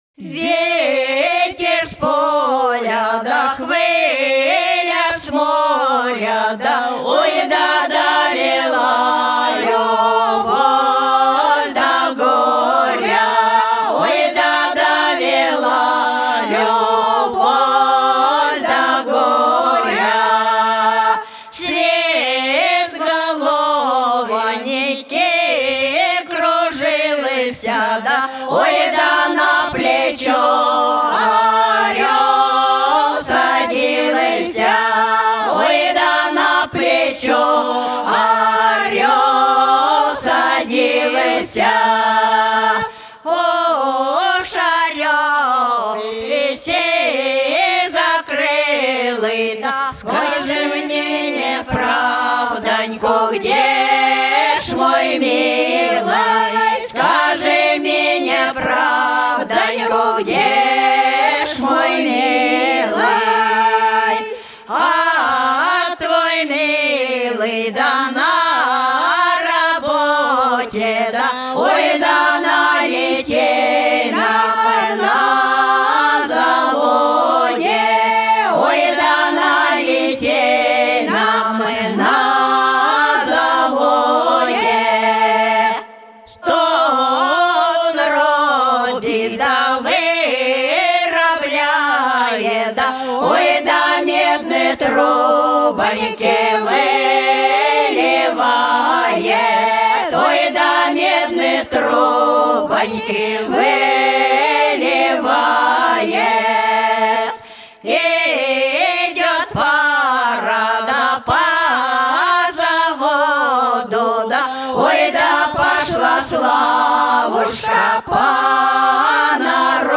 (лирическая)